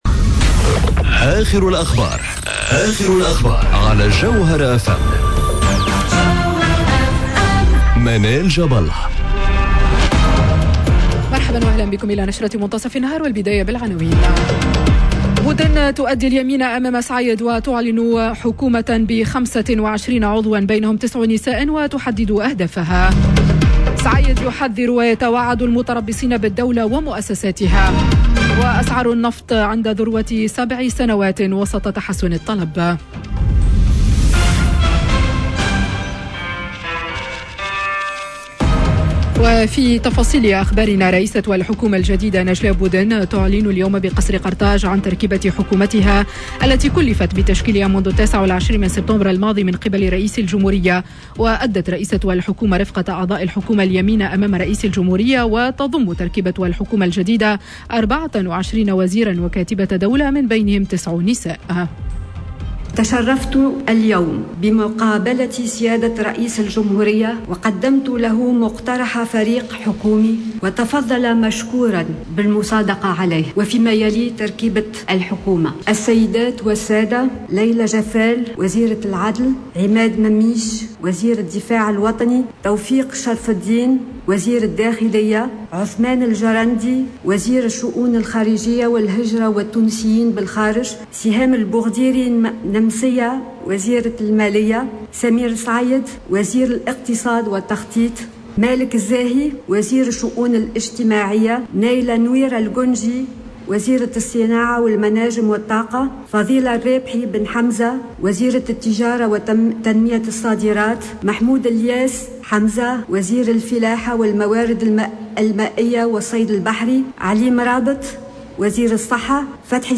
نشرة أخبار منتصف النهار ليوم الإثنين 11 أكتوبر 2021